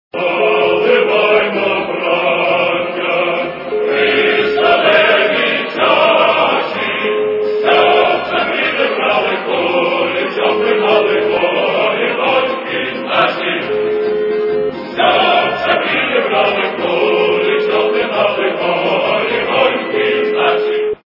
- народные